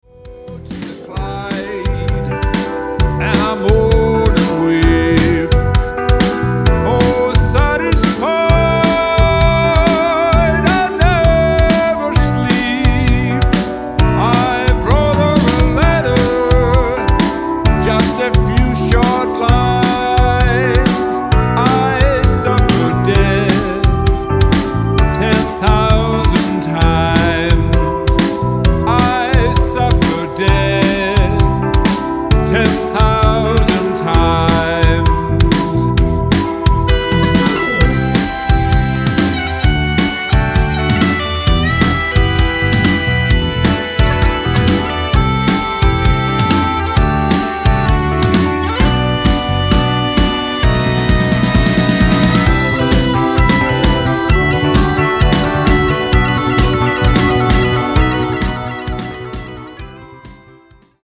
Il Gruppo di musica folk & celtica